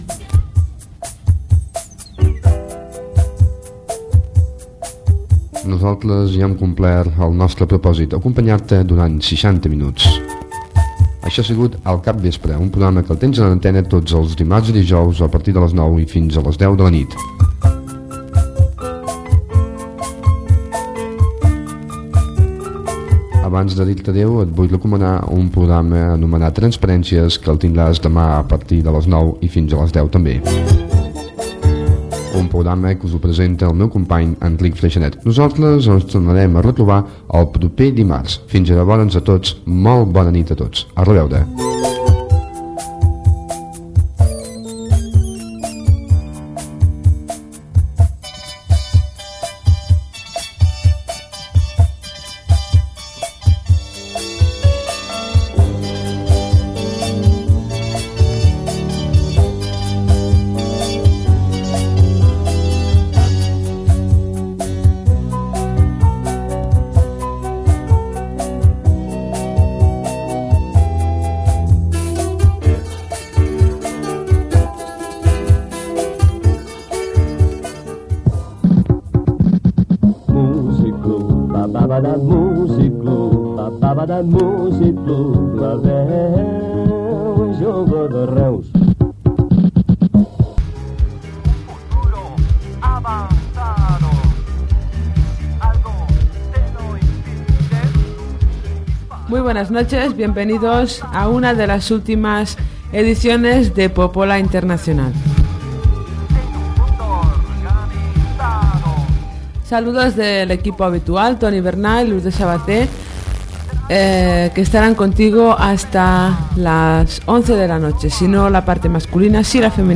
Final del programa "Al capvespre", indicatiu i inici de "Popola Internacional".
Musical
FM